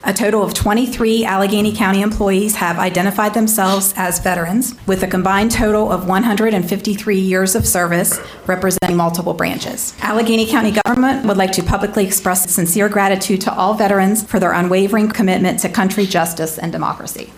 Veterans Honored At County Meeting